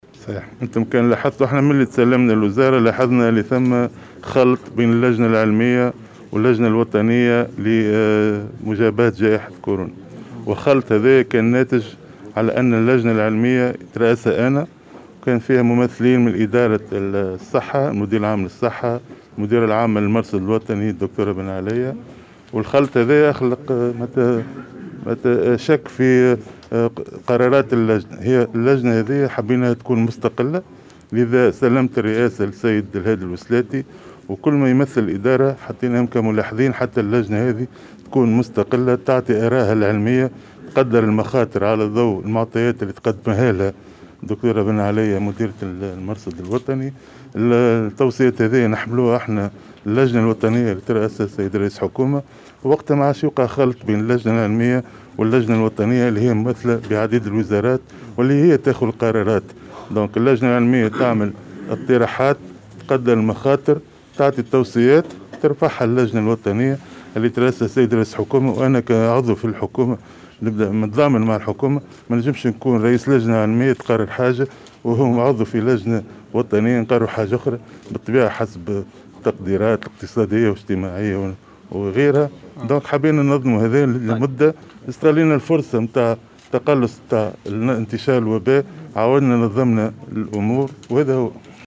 وزير الصحة